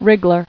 [wrig·gler]